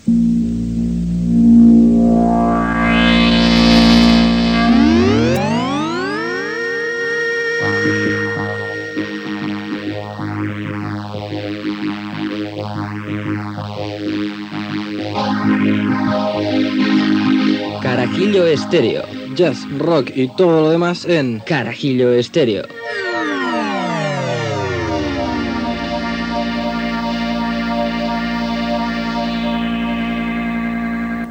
Careta del programa Gènere radiofònic Musical